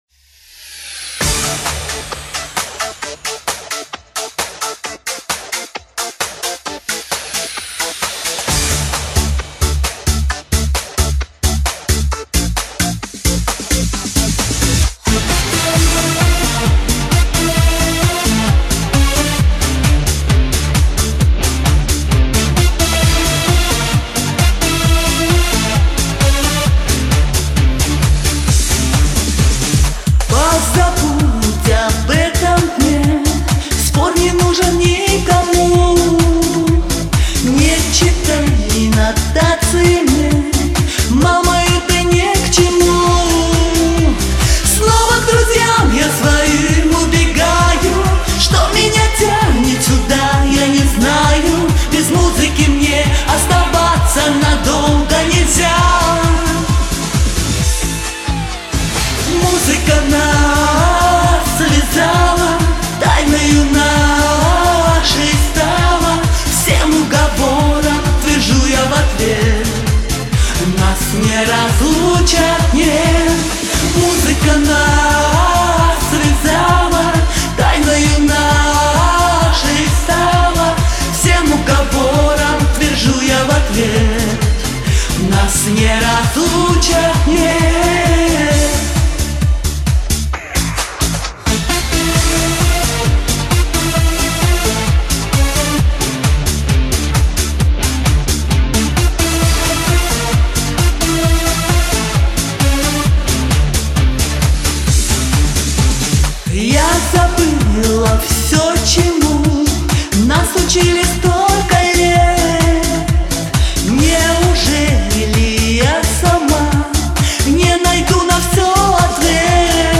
сильная соперница!!!!здорово!!!хорошо поет